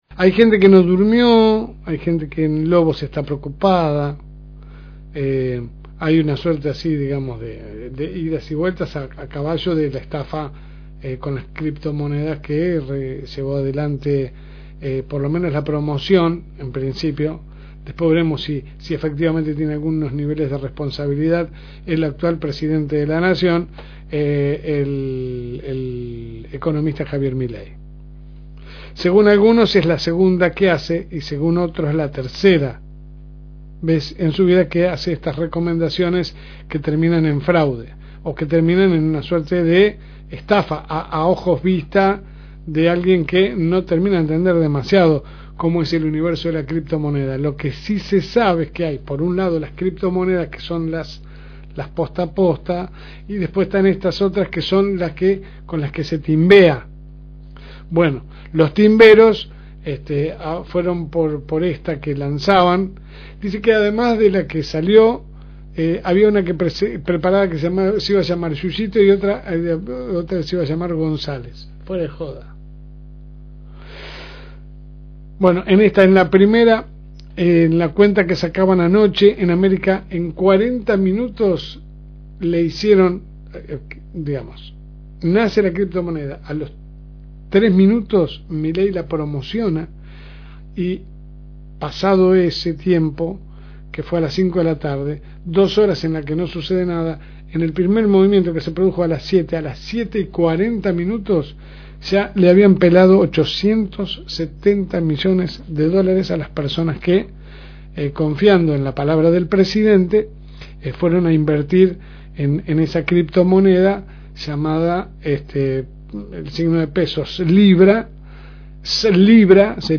AUDIO – Editorial de LSM – FM Reencuentro